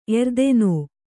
♪ erdenō